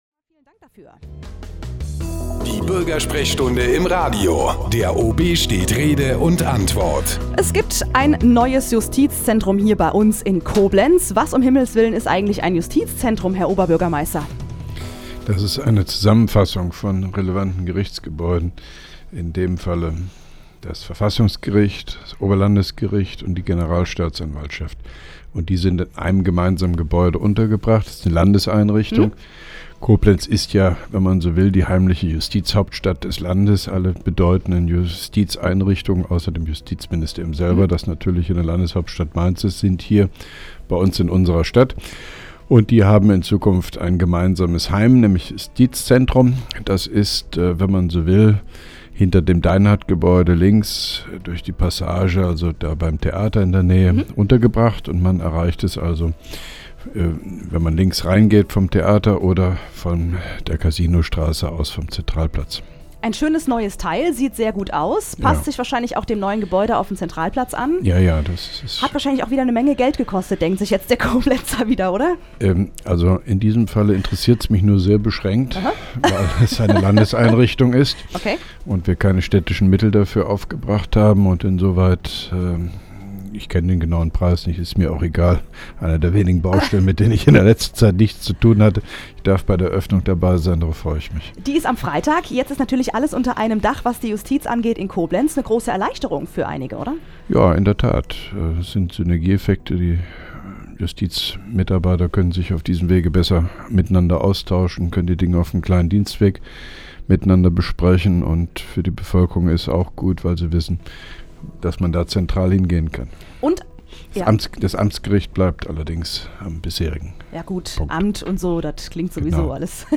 Interviews/Gespräche